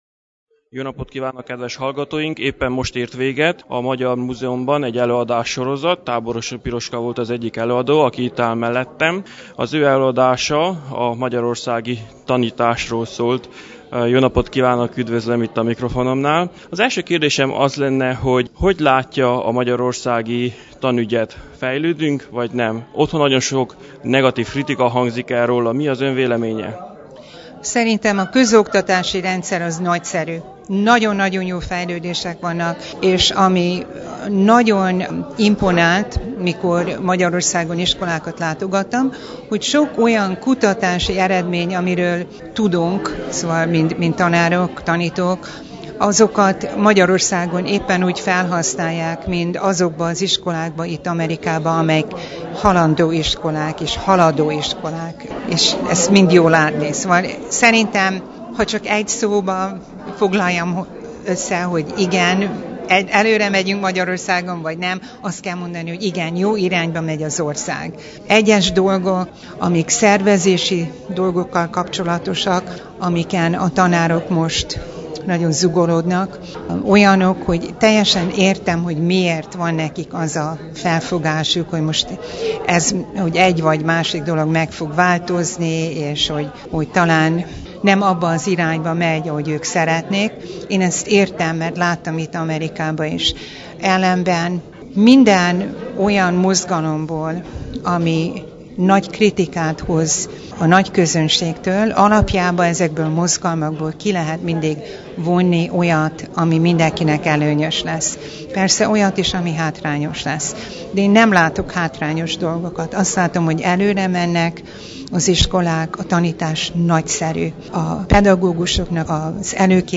Ezt hallgassuk most meg.